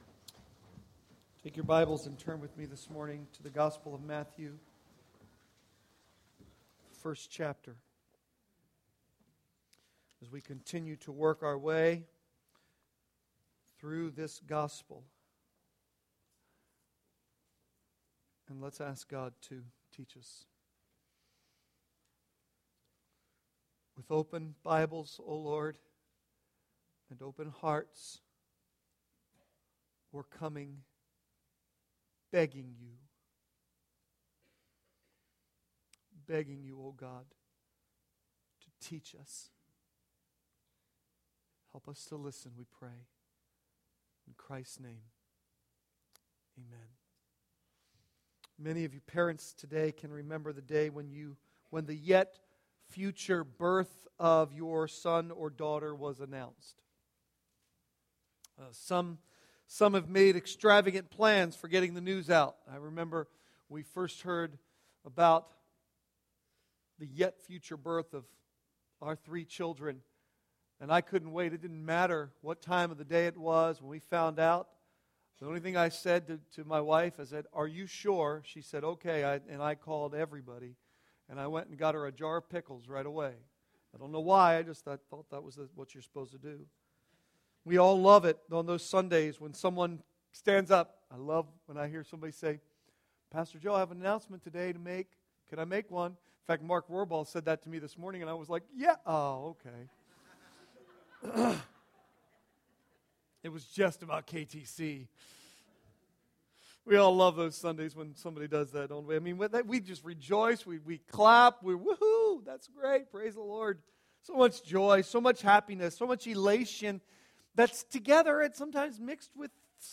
Sermons Archive - Page 68 of 90 - Calvary Bible Church - Wrightsville, PA